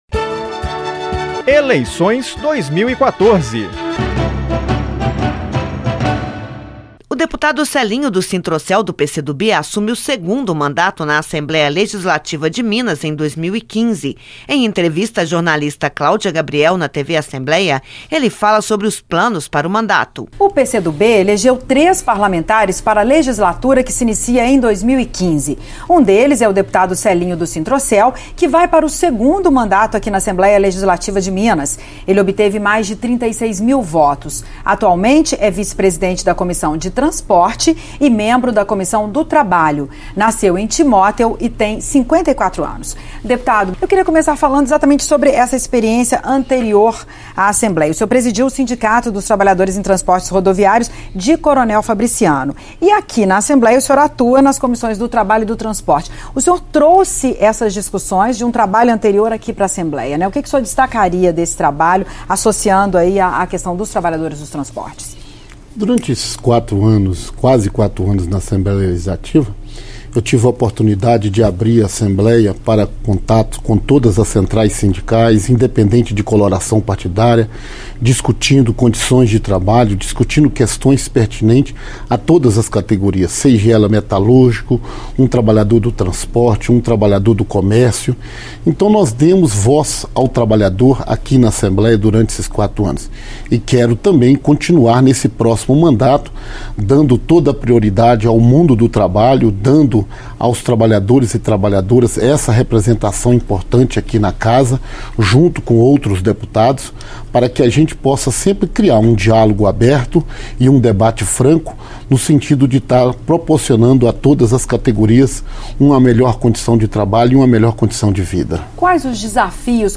Eleições 2014 - Deputado Celinho do Sinttrocel (PC do B) fala sobre metas para o 2º mandato
Entrevistas